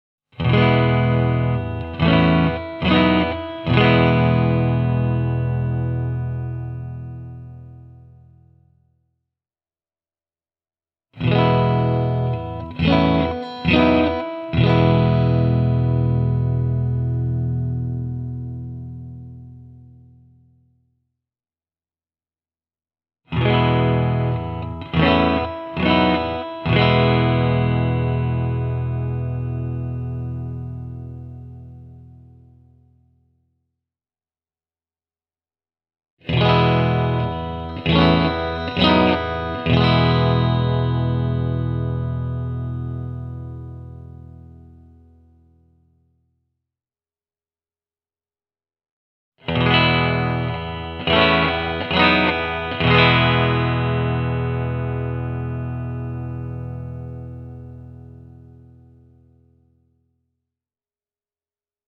SSL-1-version soundissa on aitoa vintage-mojoa. Kitara on erittäin dynaaminen, ja ääni on kuivahko maiskuttavalla atakilla ja aimolla annoksella purevuutta: